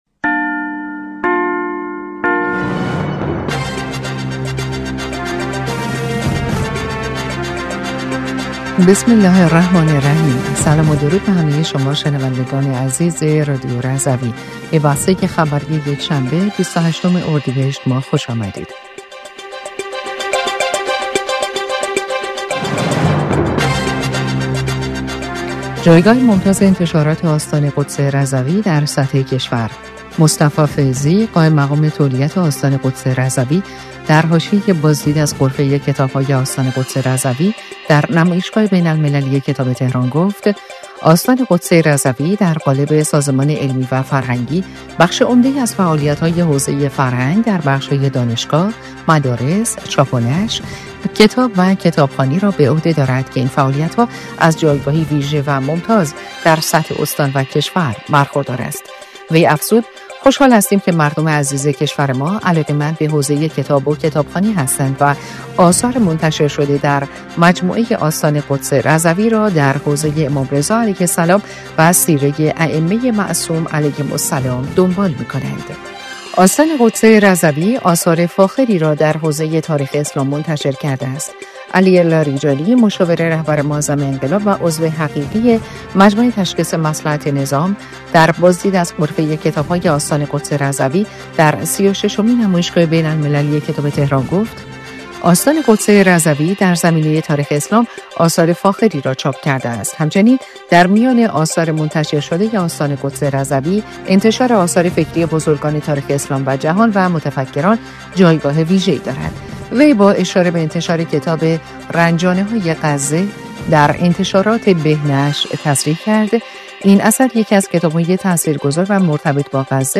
بسته خبری ۲۸ اردیبهشت ماه رادیو رضوی/